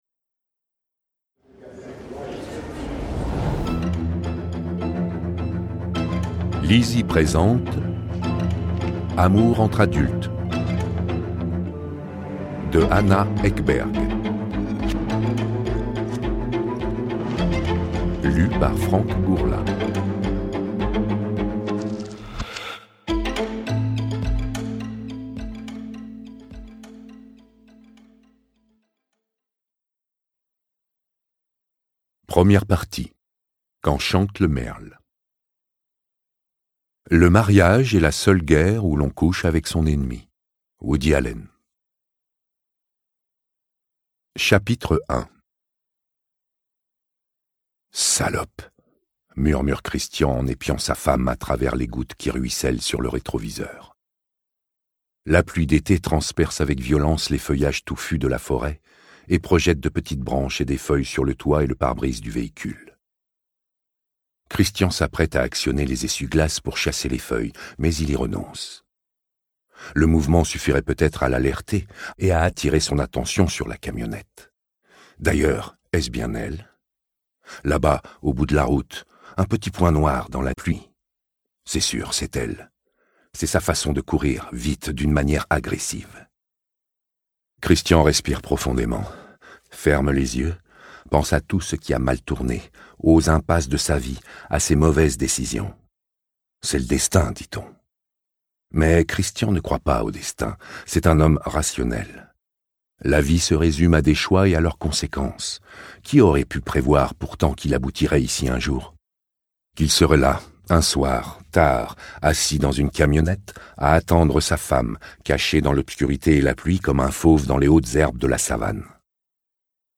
Extrait gratuit - Amour entre adultes de Anna EKBERG